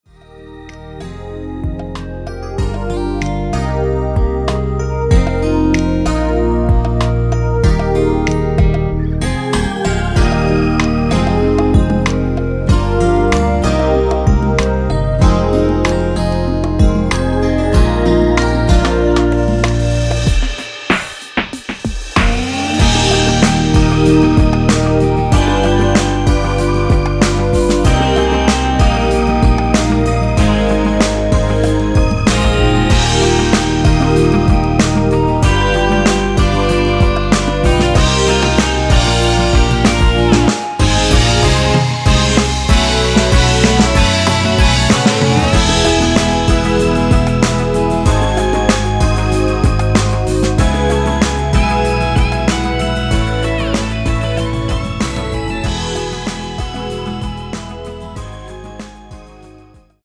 원키에서(-1)내린 (짧은편곡) 맬로디 포함된 MR입니다.(미리듣기 참고)
Eb
앞부분30초, 뒷부분30초씩 편집해서 올려 드리고 있습니다.
중간에 음이 끈어지고 다시 나오는 이유는